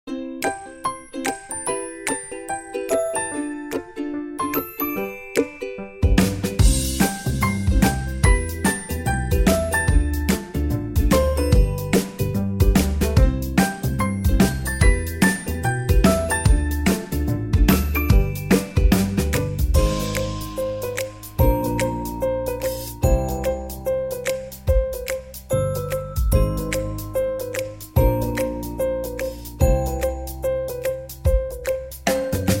Играет музыка (
- Ребята, какие чувства вызывает у вас эта мелодия? (Праздник)